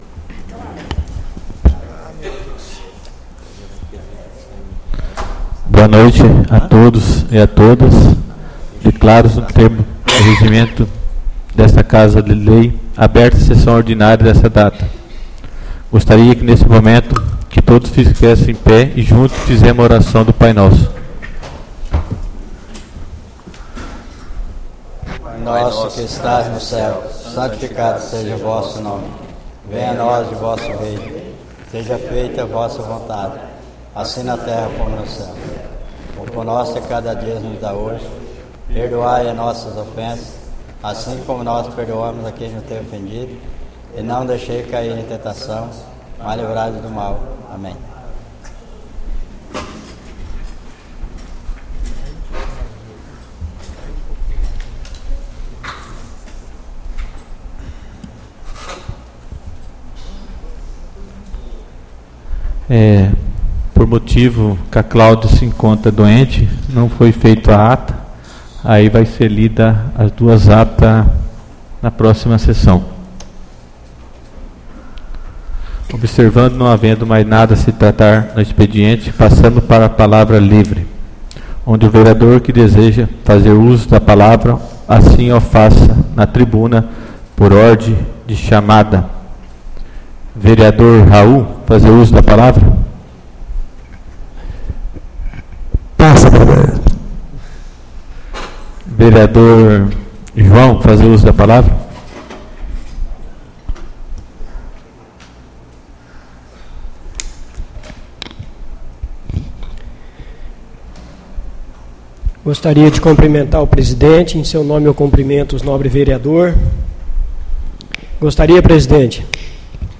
Áudio da Sessão Ordinária 14/05/2019